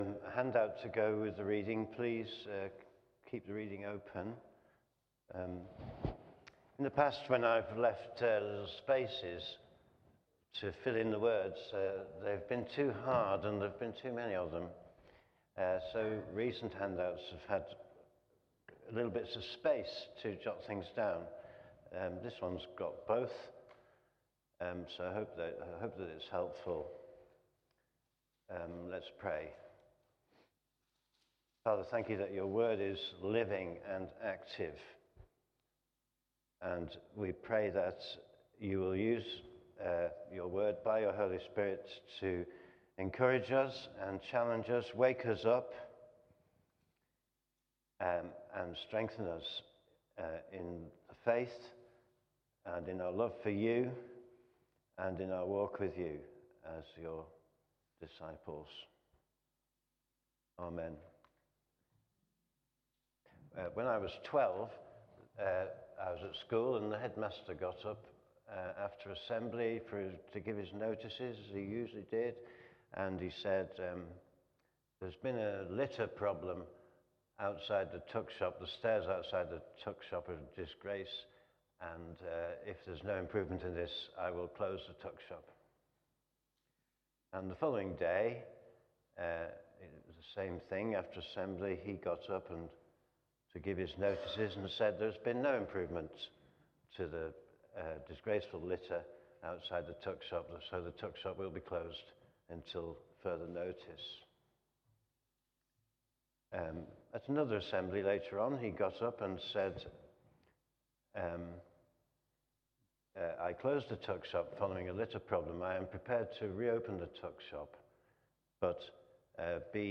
Media Library The Sunday Sermons are generally recorded each week at St Mark's Community Church.
Theme: Living in the light of eternity Sermon